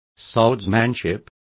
Also find out how esgrima is pronounced correctly.